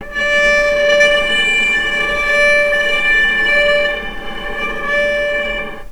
healing-soundscapes/Sound Banks/HSS_OP_Pack/Strings/cello/sul-ponticello/vc_sp-D5-mf.AIF at bf8b0d83acd083cad68aa8590bc4568aa0baec05
vc_sp-D5-mf.AIF